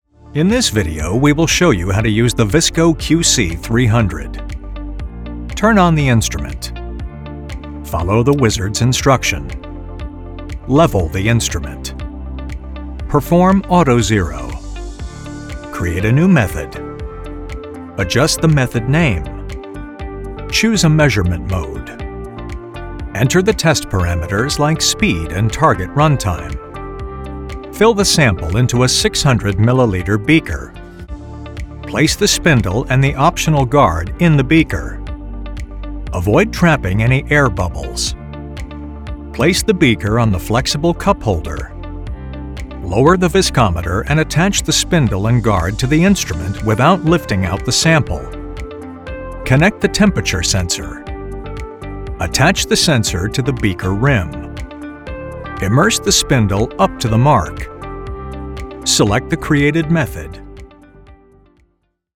Male
English (American)
Explainer Videos